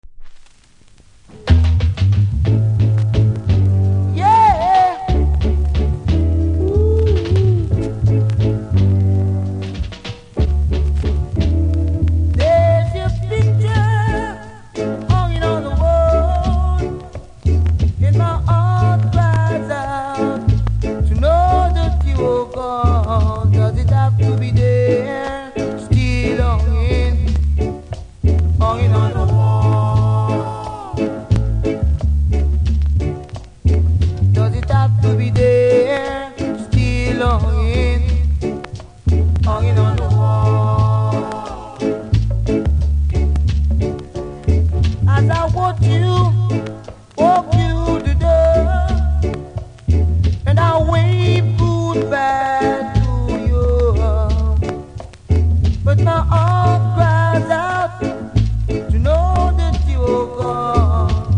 見た目はキズ少なめですがプレス起因なのかノイズ多めです。
このぐらいノイズあった方が、RAE TOWN みたいですが・・・。